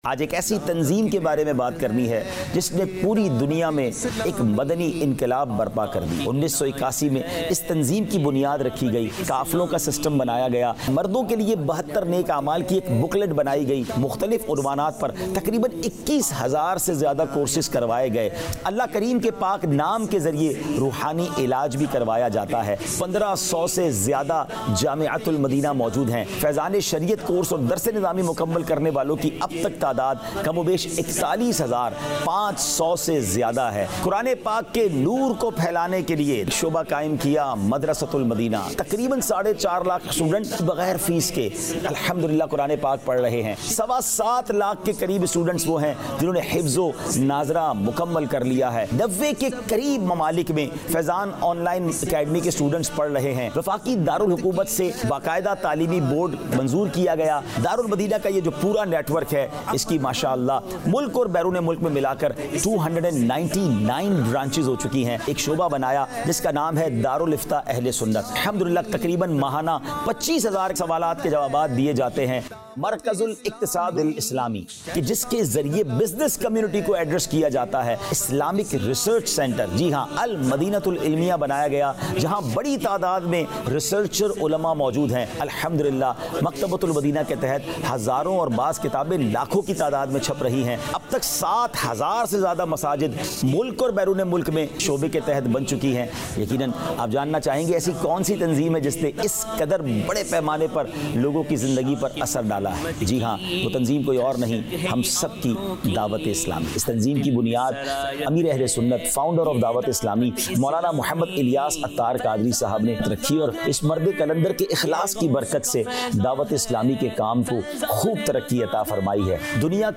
Introduction of Dawateislami | 56 Minutes Documentary 2026